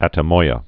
tə-moiə, ătə-)